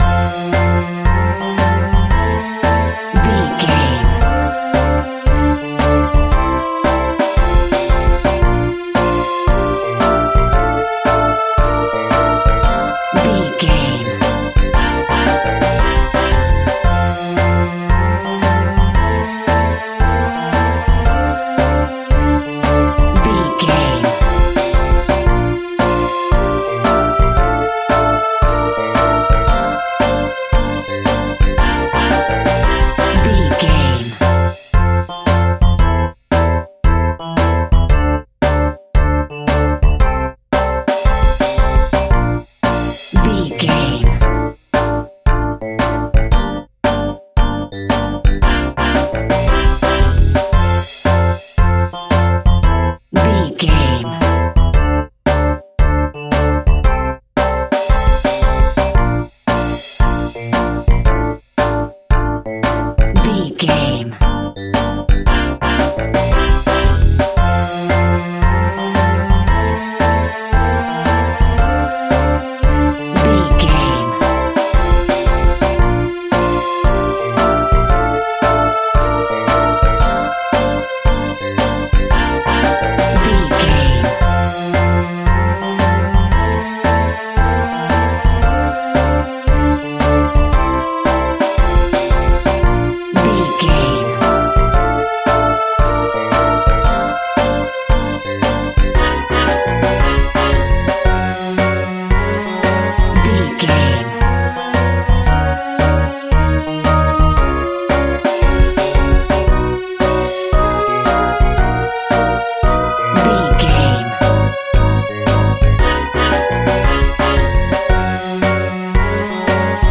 Ionian/Major
pop rock
indie pop
energetic
uplifting
cheesy
drums
bass guitar
piano
hammond organ
synth